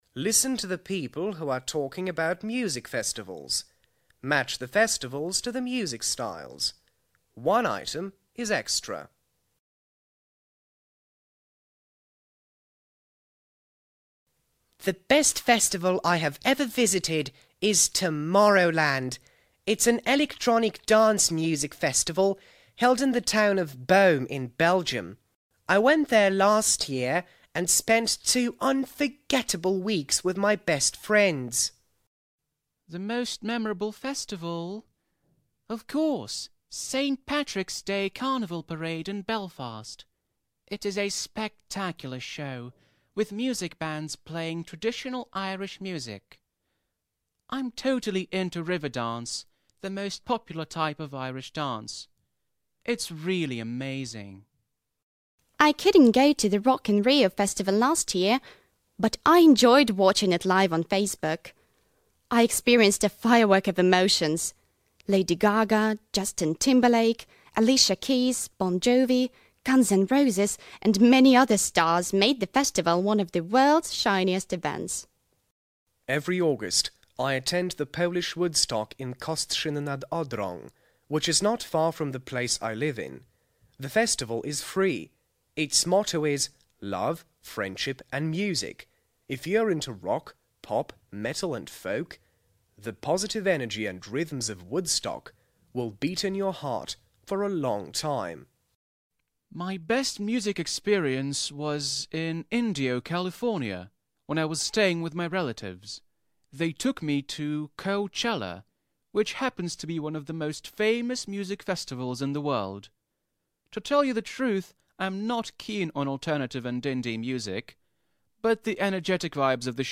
Listen to the people who are talking about music festivals. Match the festivals to the music styles.